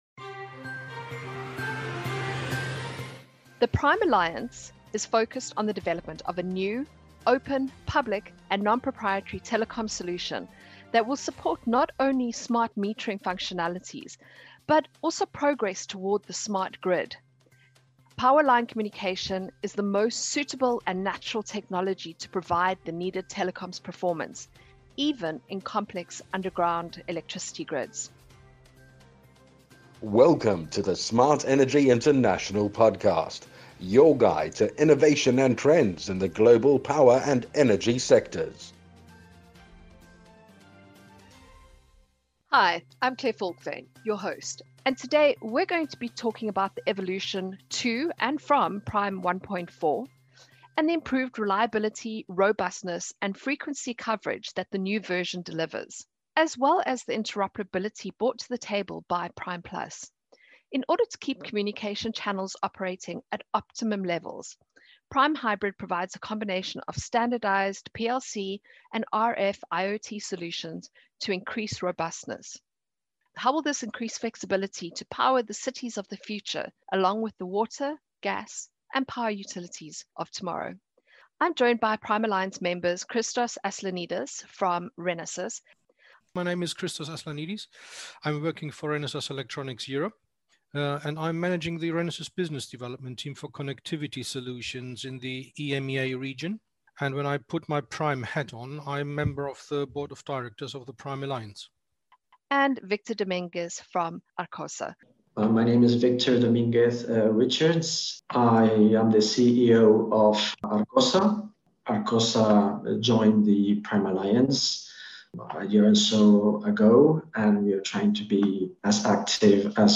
In this podcast episode, industry experts from the PRIME Alliance and Arkossa Smart Solutions discuss the secrets of reliable smart meter communication and the importance of industry standards.